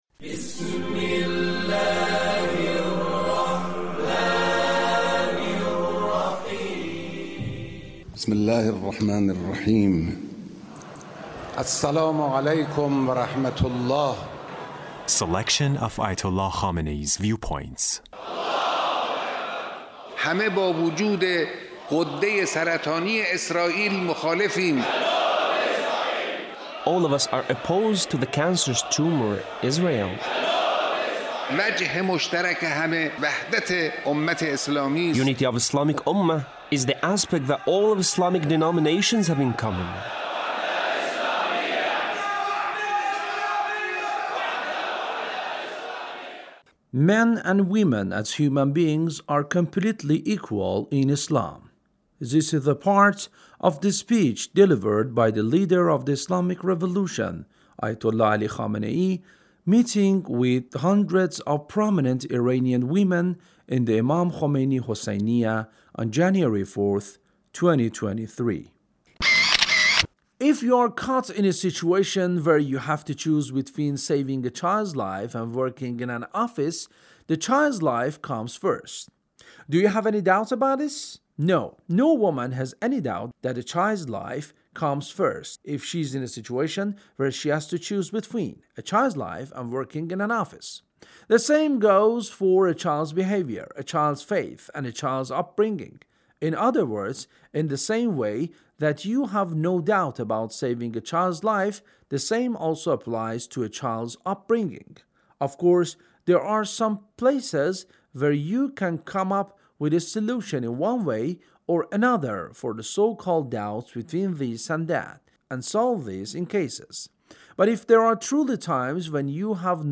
Leader's Speech meeting with hundreds of prominent Iranian women